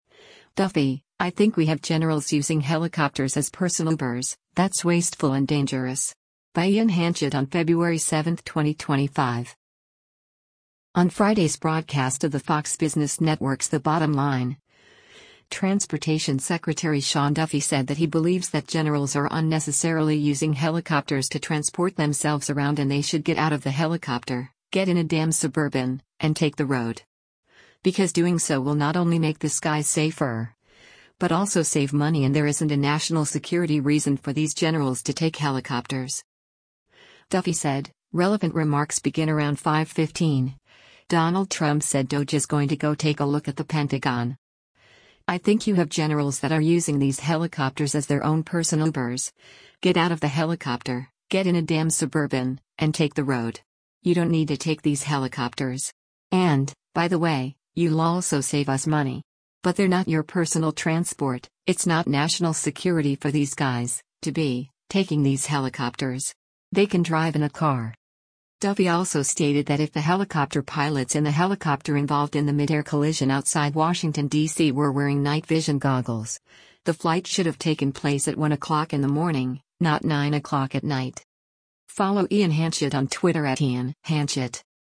On Friday’s broadcast of the Fox Business Network’s “The Bottom Line,” Transportation Secretary Sean Duffy said that he believes that generals are unnecessarily using helicopters to transport themselves around and they should “get out of the helicopter, get in a damn Suburban, and take the road.”